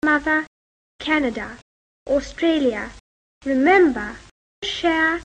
And here Kate Winslet with the same [ʌ]/[ə] variability: